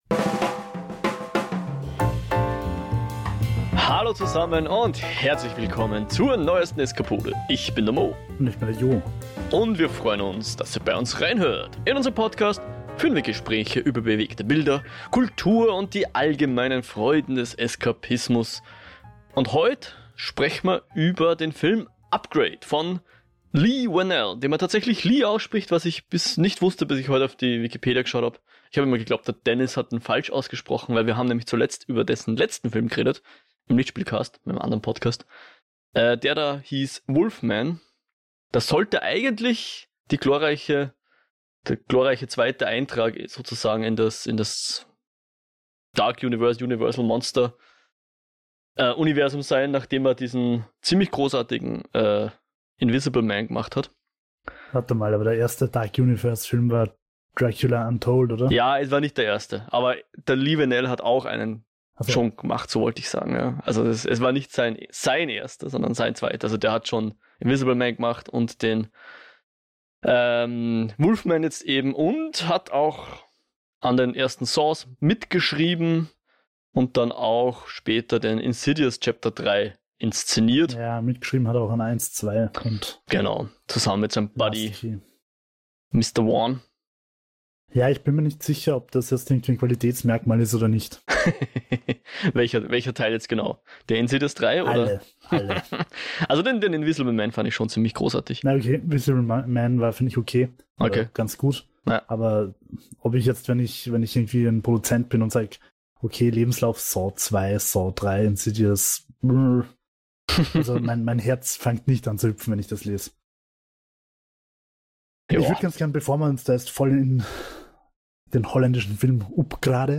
Gespräche über bewegte Bilder, Kultur und die allgemeinen Freuden des Eskapismus